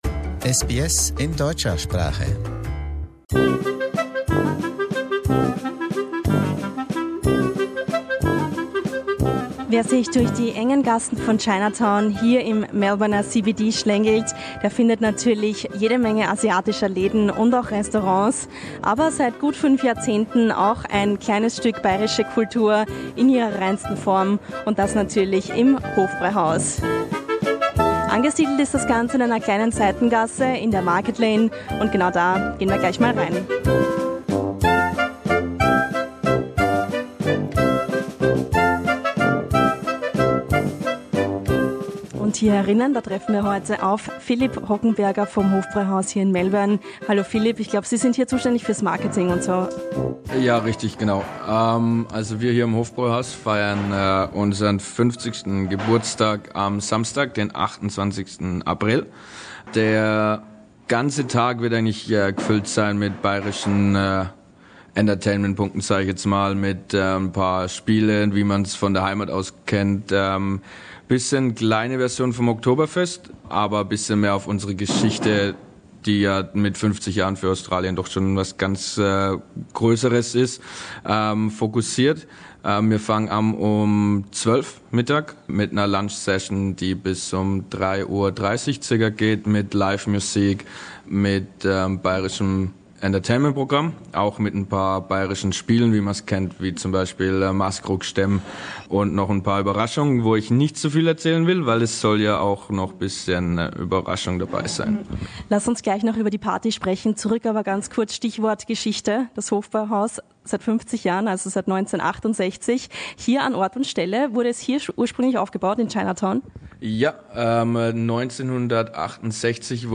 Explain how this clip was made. Sein 50-jähriges Bestehen feiert das Hofbräuhaus im Herzen der Melbourner Innenstadt am 28. April mit einer großen Party. SBS fragte vor Ort nach, auf welch bewegende Geschichte das Restaurant zurückblickt.